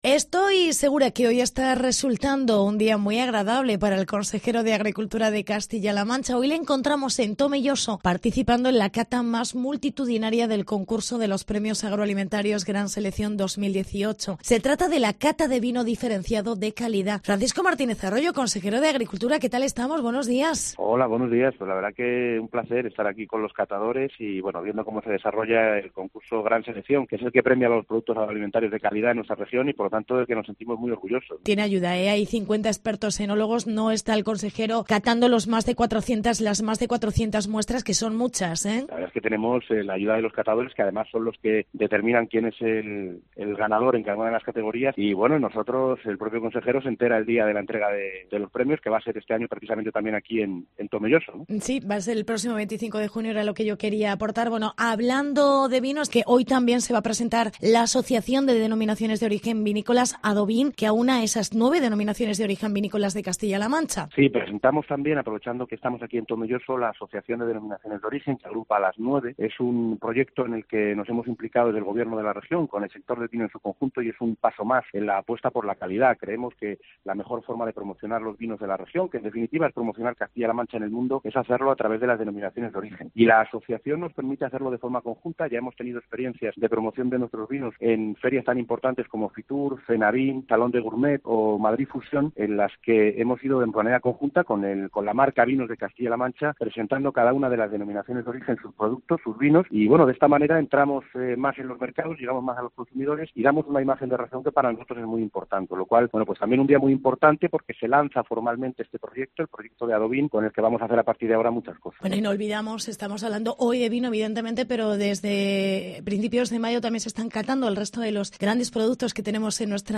Entrevista con el Consejero: Francisco Martínez Arroyo